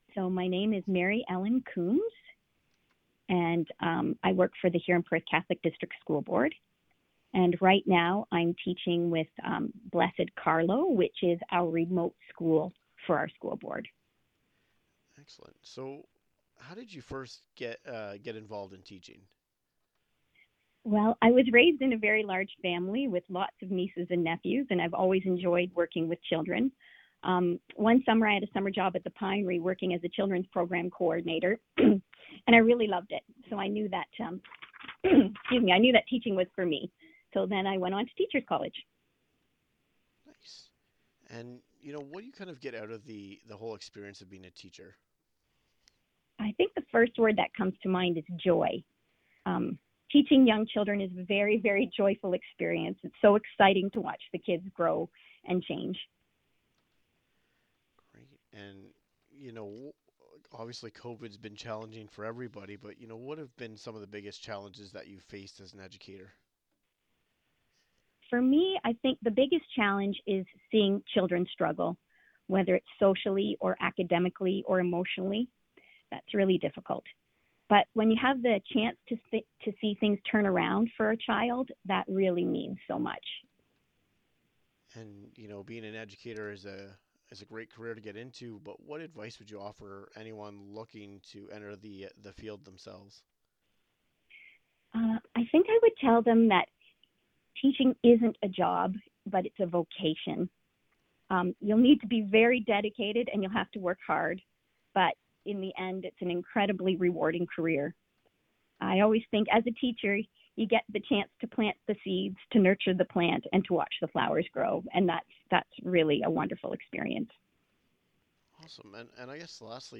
The full interview can be found below: